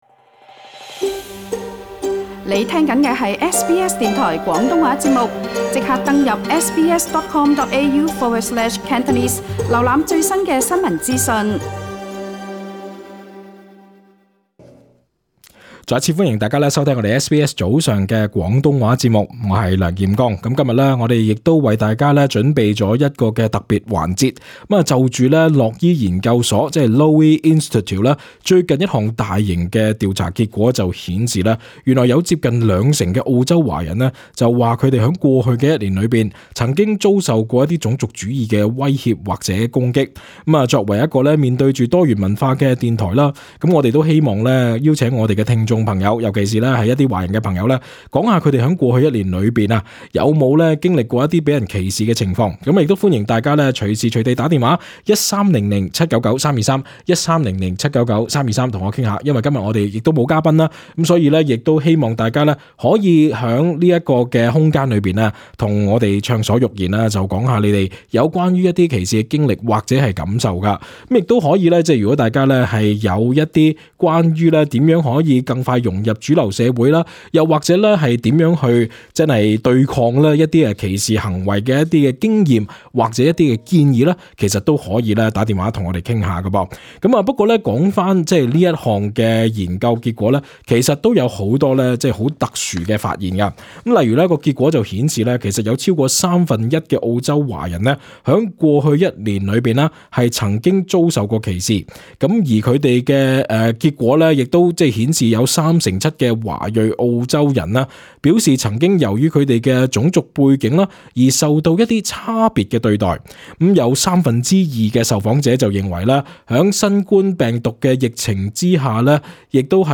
疫情下华人易受歧视？致电听众普遍认为澳洲好和谐